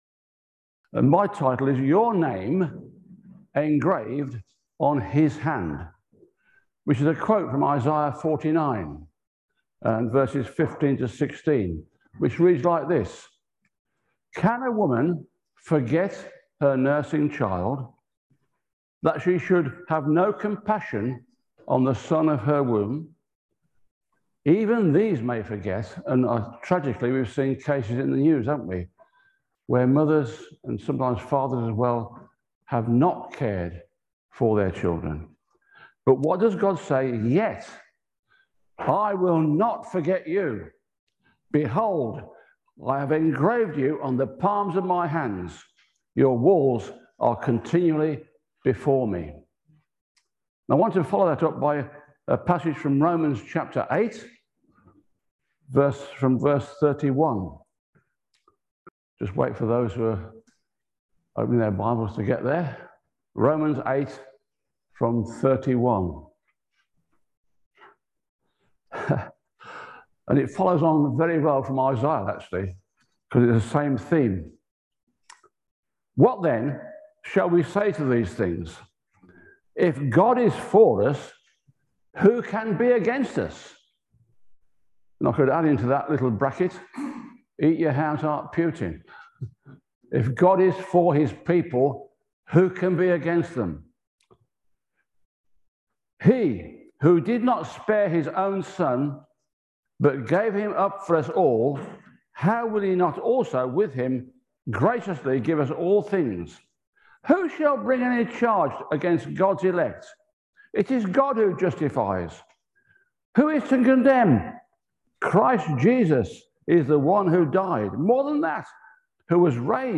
Passage: Isaiah 49:15-16, Romans 8:31-37 Service Type: Sunday Service